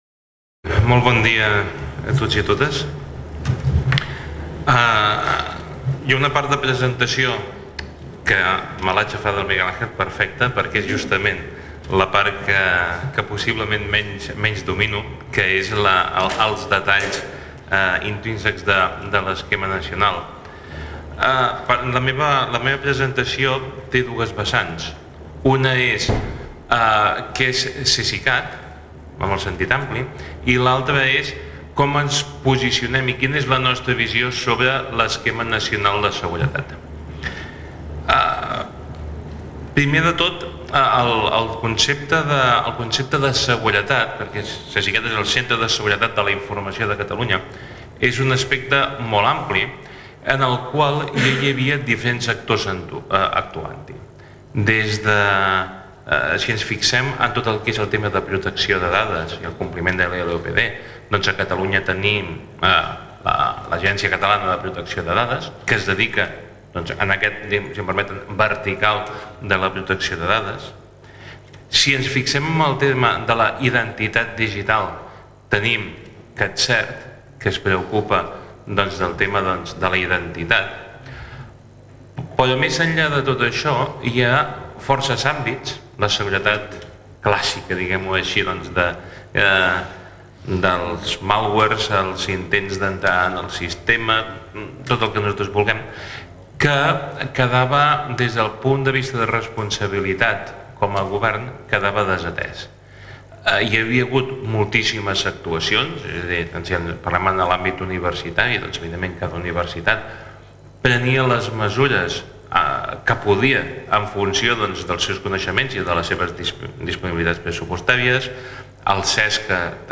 Taula rodona sobre l'esquema nacional de seguretat [II]